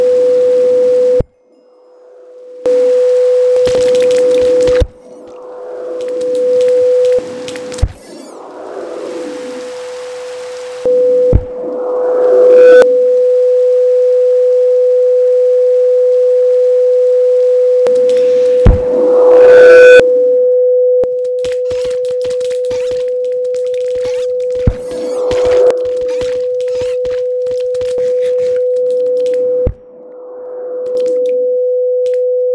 1. After the guardian kill sound is played, every sound in the system is attenuated. Then over a short period, the sound is gradually going back to normal level
2. Apart from the attenuation in point 1, the sound then gradually amplifies further, beyond the normal level it was before the guardian kill sound was played. Then, the sound instantly goes back to normal level
A 500 Hz signal sine was played all the time with occasional guardian death sounds to show the distortions.